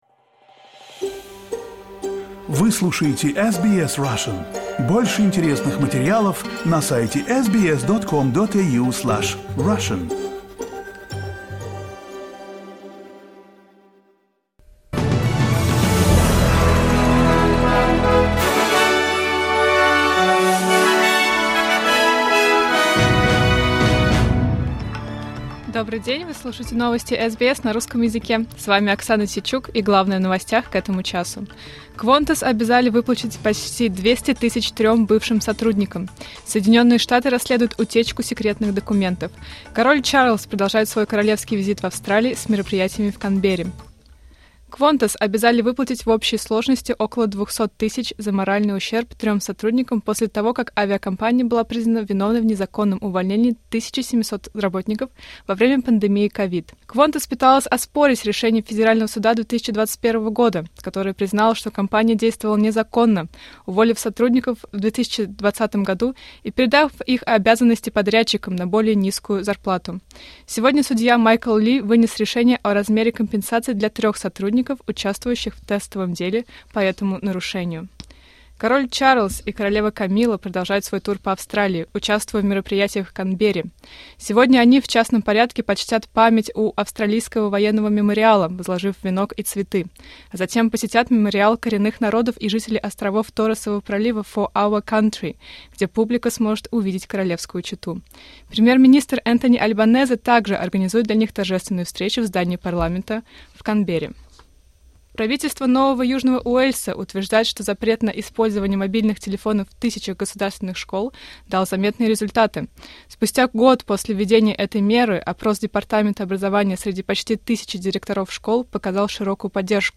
Новости SBS на русском языке — 21.10.2024